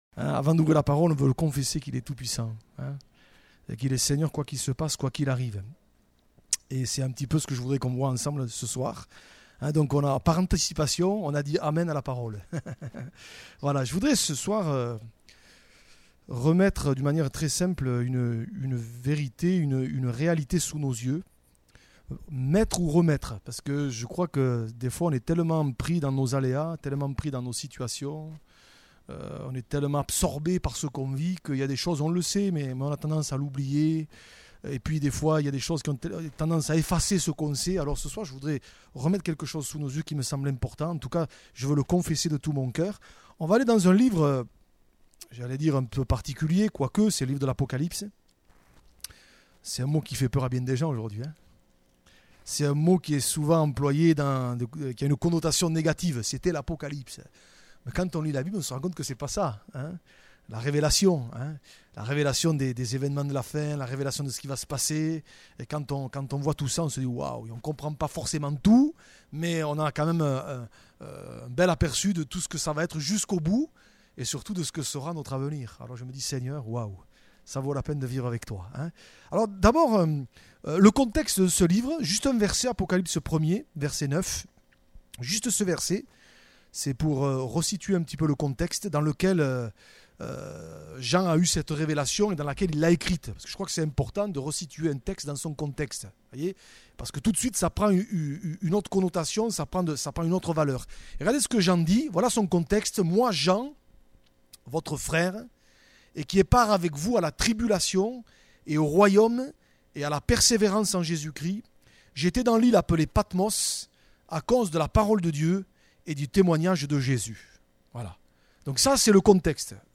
Type De Service: Etude Biblique